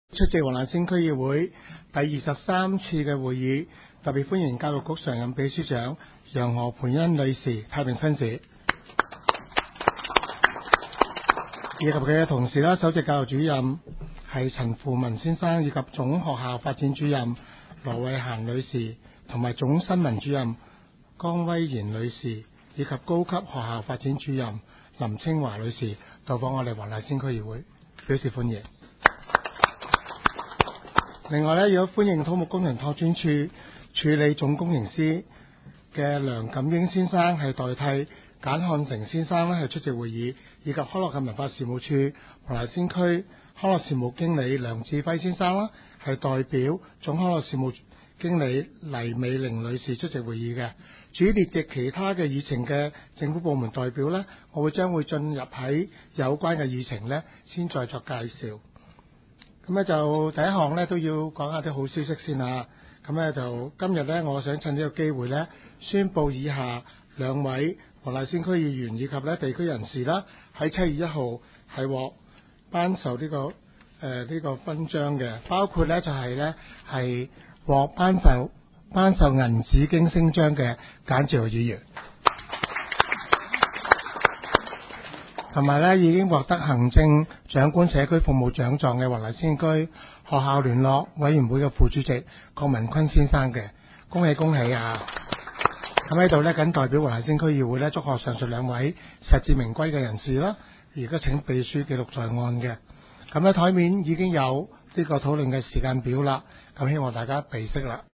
区议会大会的录音记录
黄大仙区议会第二十三次会议
黄大仙区议会会议室
主席致辞